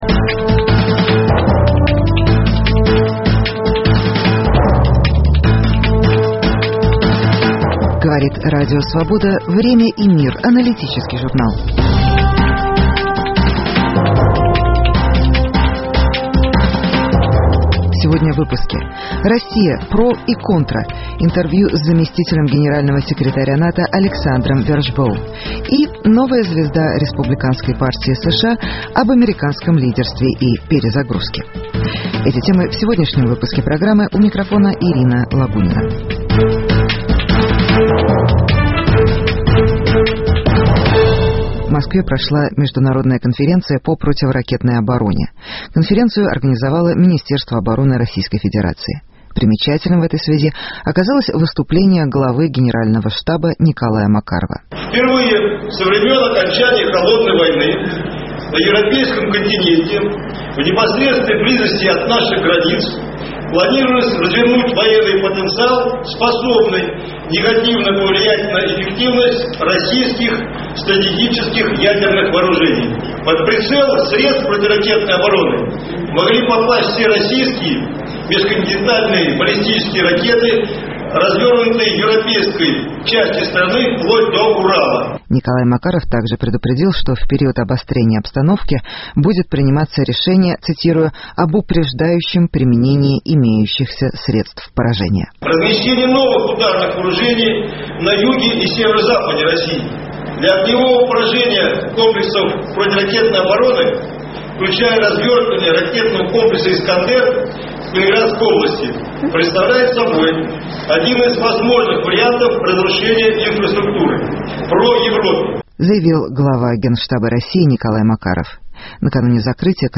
Интервью с заместителем Генерального секретаря НАТО Александром Вершбоу. Новая звезда Республиканской партии США Марко Рубио о глобальном лидерстве Америки и политике "перезагрузки".